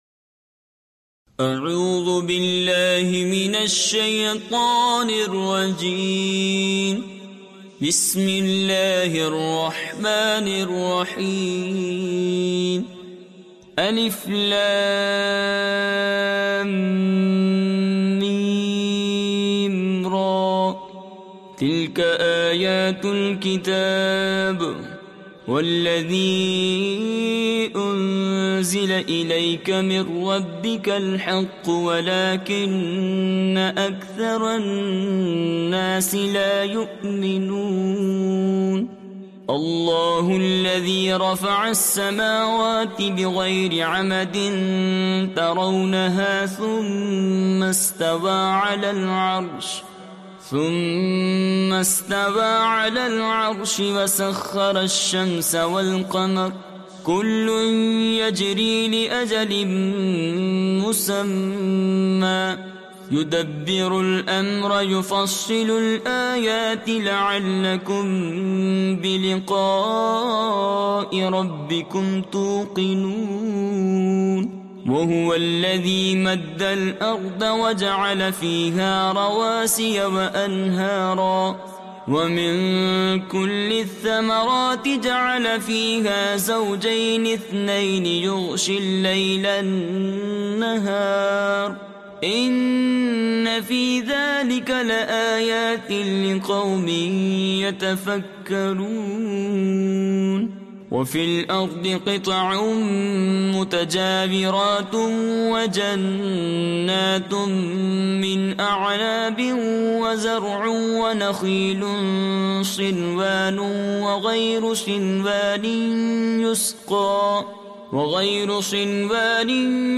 From this page you can Read Surah Ar-Rad online and listen to it in mp3 audio recitations also you can download it in PDF with Urdu and English translations for better understanding.